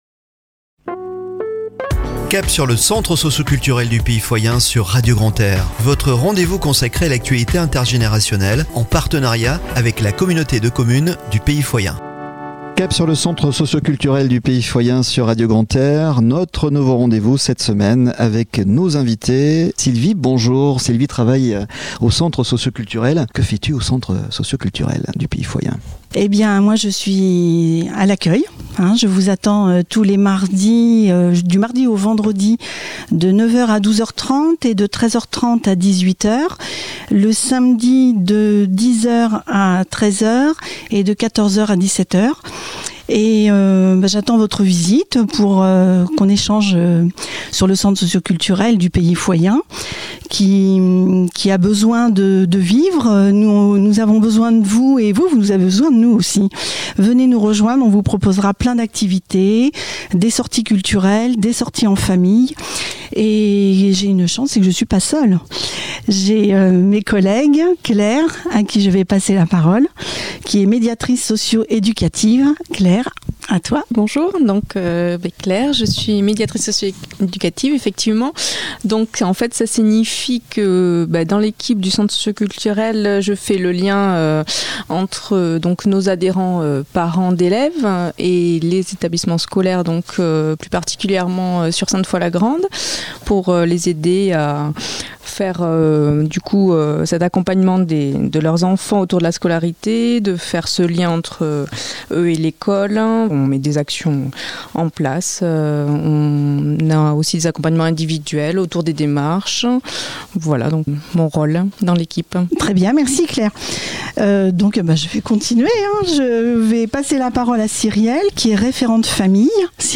A écouter chaque Lundi à 09h30 et 17h30 , et Mercredi à 12h30 et 19h30 sur Radio Grand "R" en partenariat avec la Communauté de Communes du Pays Foyen ????